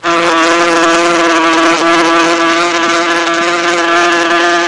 Hornet Sound Effect
Download a high-quality hornet sound effect.
hornet-2.mp3